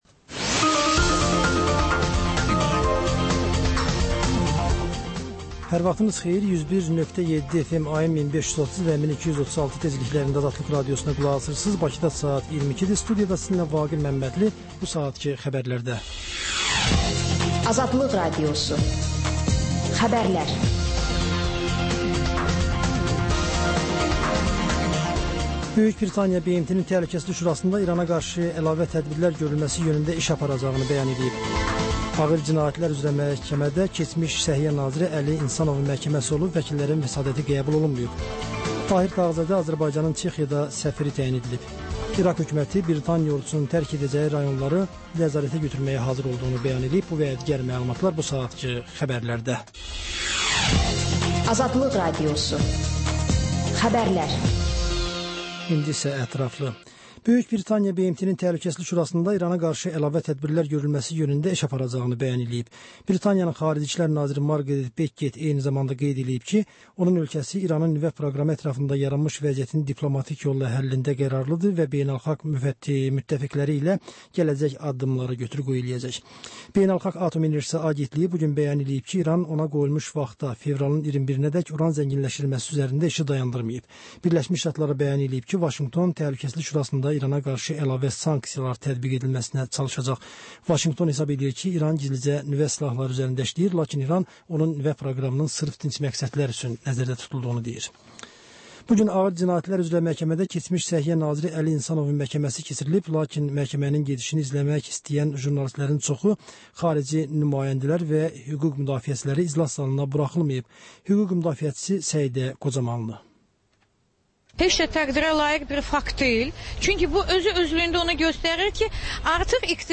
Xəbər, reportaj, müsahibə. Sonra: Və ən son: Qlobus: xaricdə yaşayan azərbaycanlılar.